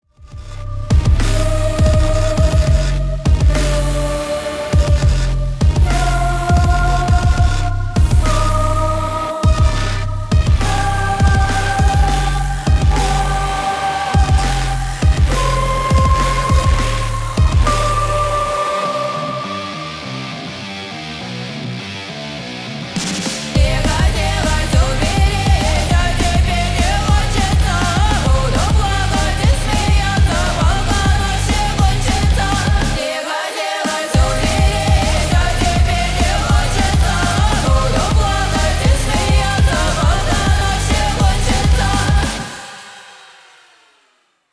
• Качество: 320, Stereo
громкие
женский вокал
мощные басы
пугающие
страшные
Industrial
electro-industrial